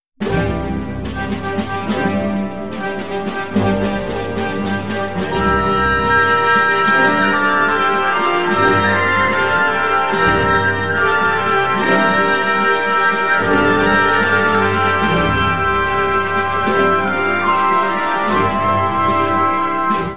classical-20-el.wav